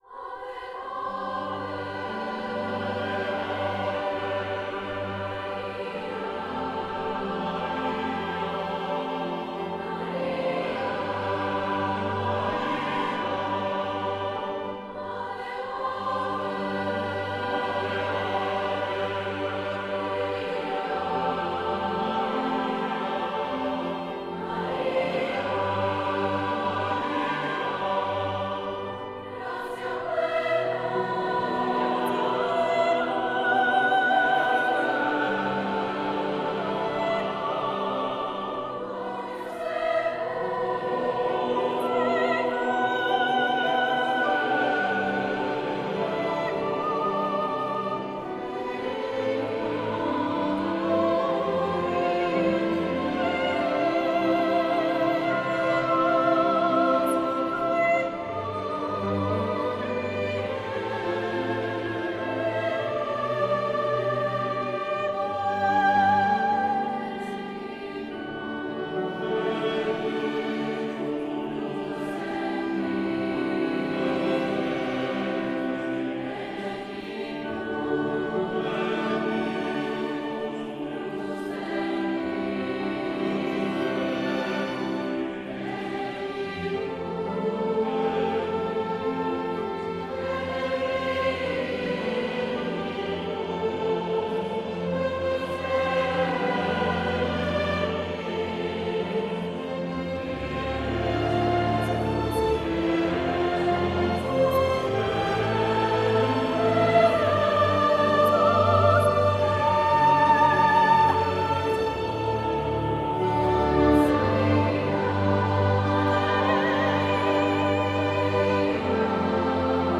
Skladba pro soprán, smíšený sbor a klavír.
verze s orchestrem